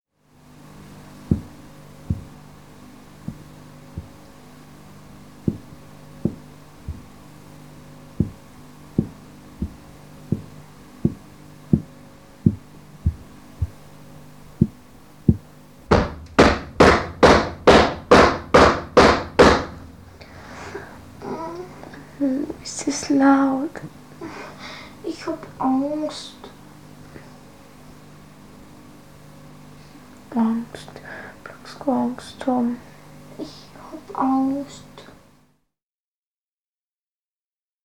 08 The Pursruck rappings, Germany, 197.mp3